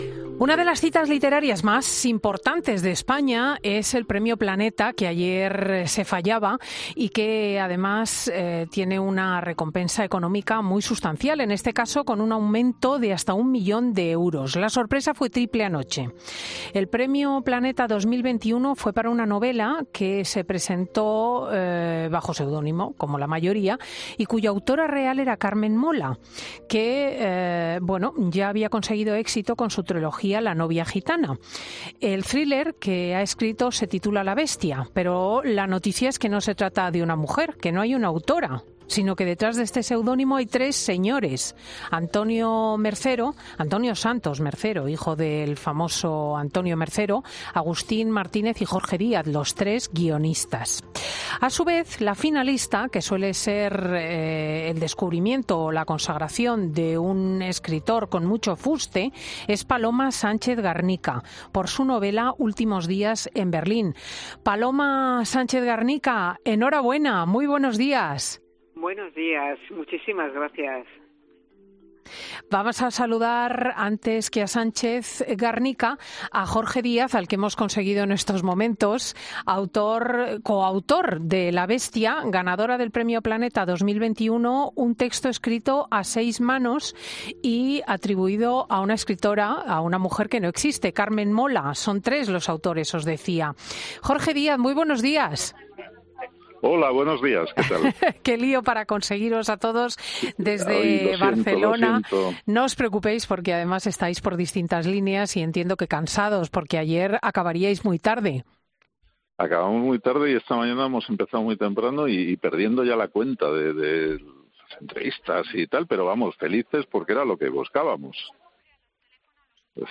Todos ellos han pasado por los micrófonos de Fin de Semana, donde Jorge Díaz, uno de los autores, confesaba que estaban “felices” porque era lo que buscaban.